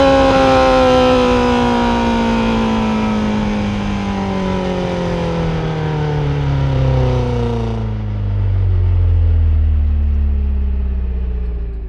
rr3-assets/files/.depot/audio/Vehicles/i4_04/i4_04_decel.wav
i4_04_decel.wav